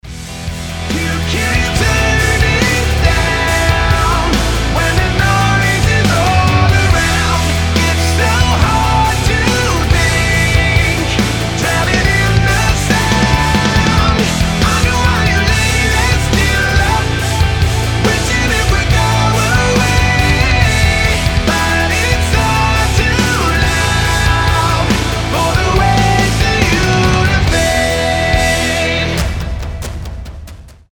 громкие
красивый мужской голос
Alternative Metal
Alternative Rock
Modern Rock